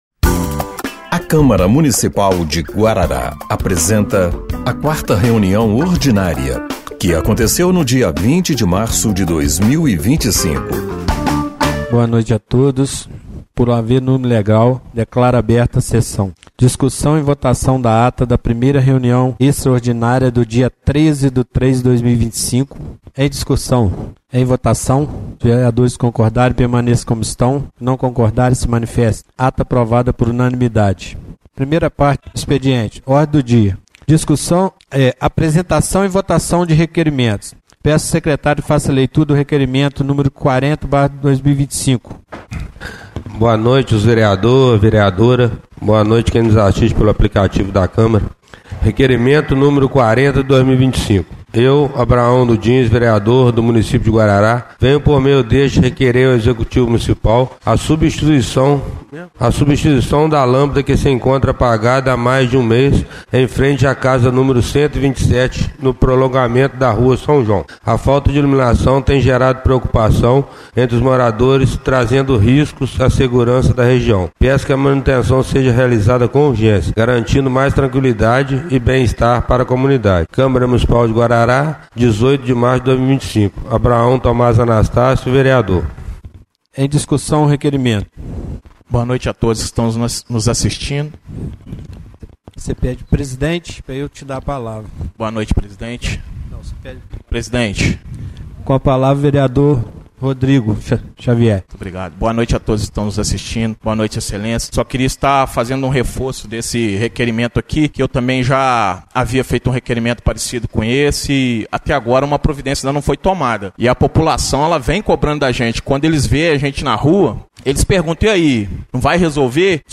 4ª Reunião Ordinária de 20/03/2025